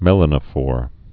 (mĕlə-nə-fôr, mə-lănə-)